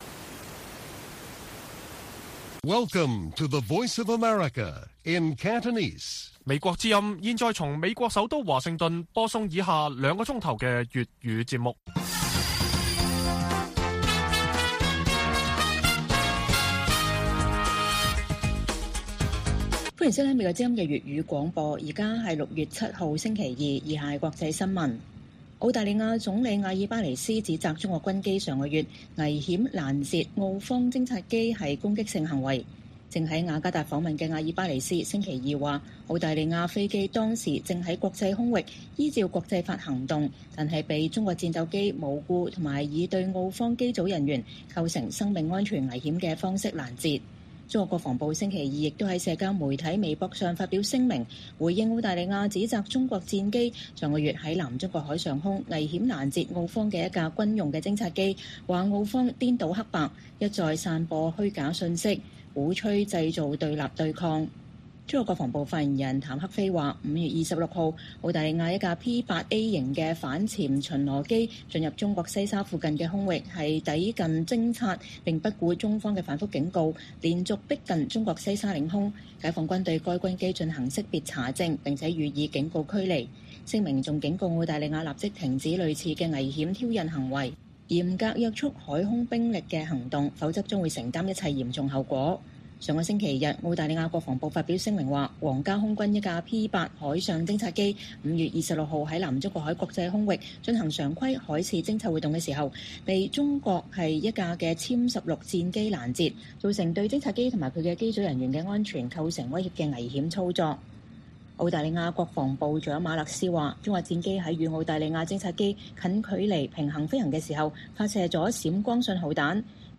粵語新聞 晚上9-10點：香港一國兩制25週年民調 林鄭月娥成歷任最低民望特首